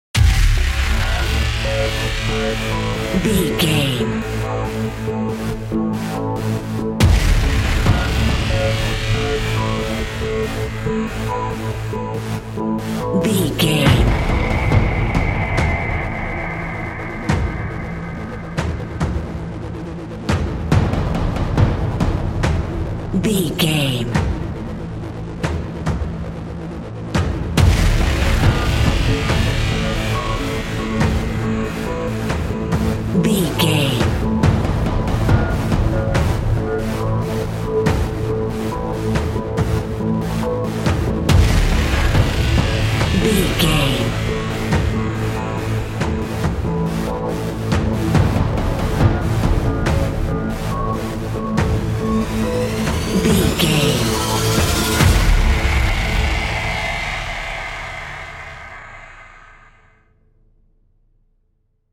Epic / Action
Aeolian/Minor
Fast
synthesiser
percussion